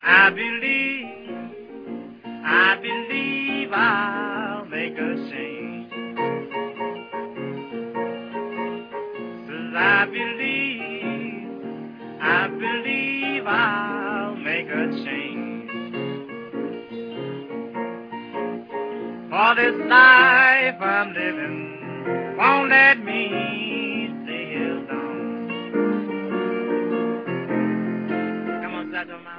вокал, гитара
пианино